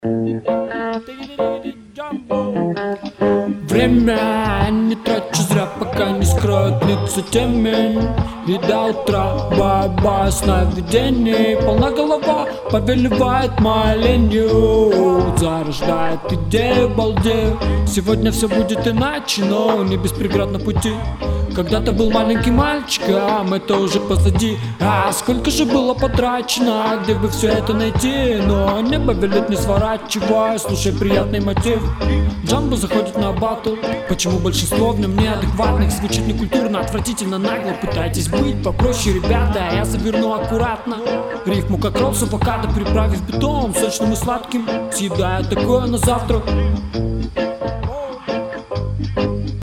Выглядит не как регги, а как реальный закос.
Хорошо спел, но слушать откровенно скучно + предсказуемые рифмы